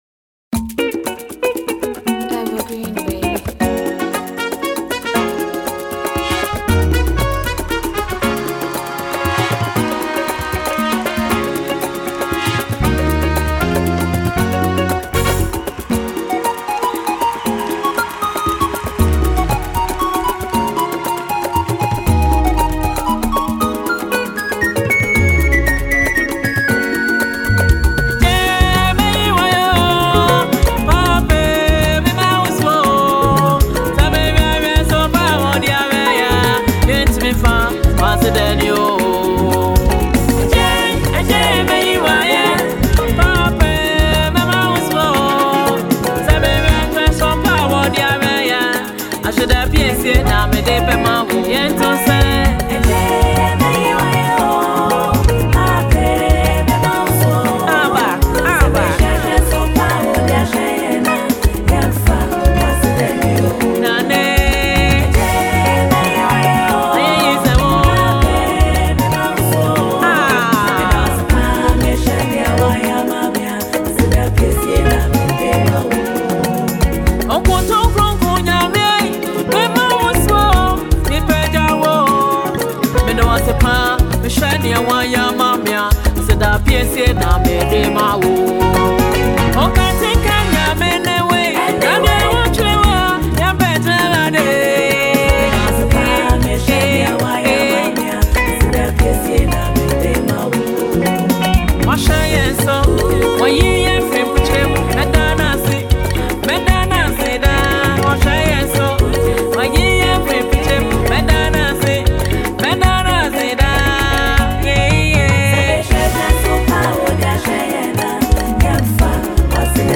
Sensational Ghanaian female gospel act
perfect blend of voices